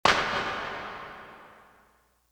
Bellyflop Clap.wav